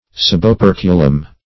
Search Result for " suboperculum" : The Collaborative International Dictionary of English v.0.48: Suboperculum \Sub`o*per"cu*lum\, n. [NL.